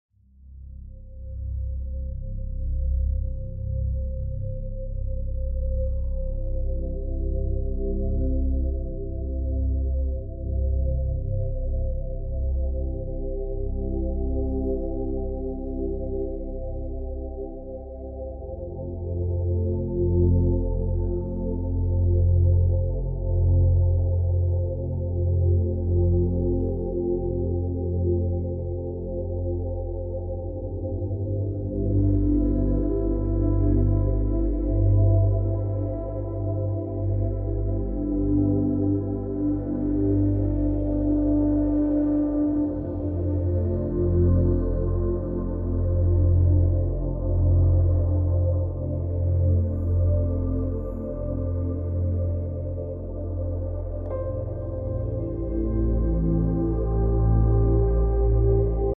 8D Audio Brain Massage.